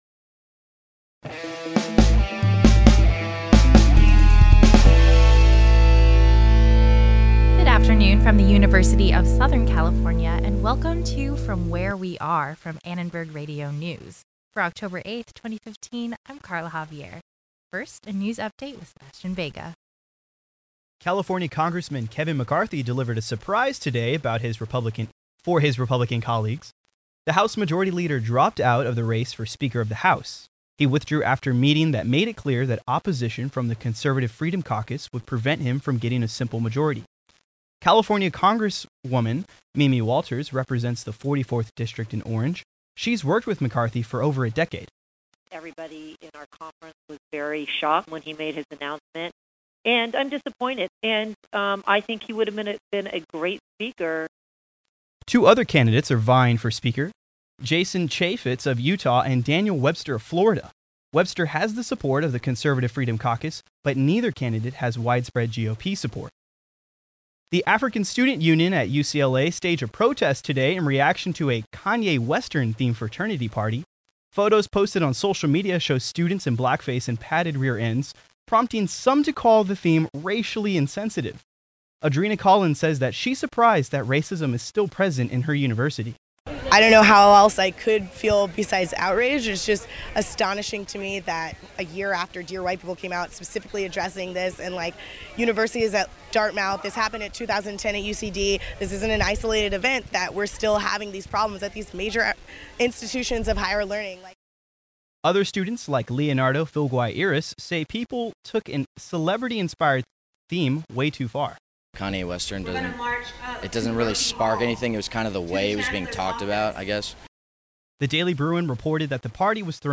ARN Live Show REEEAAAALLLLL.mp3